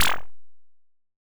bloop.wav